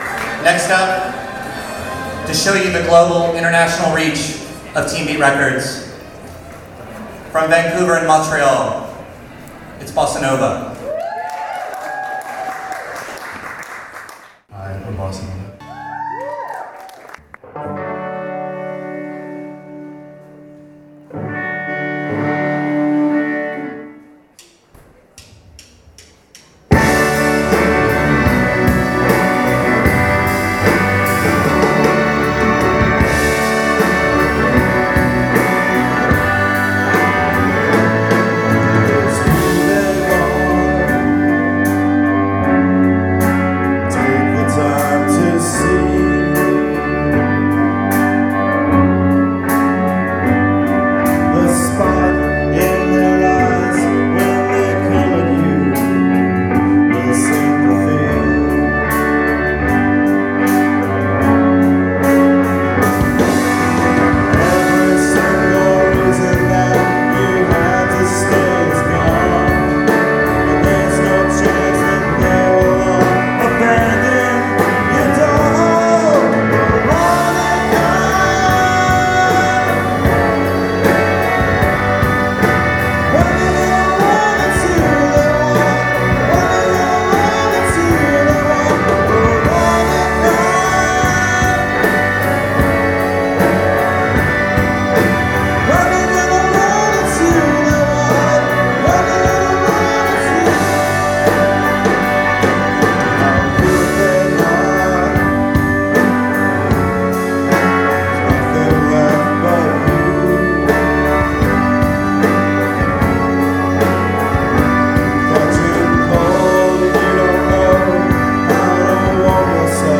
a band with nicely fully formed pop songs.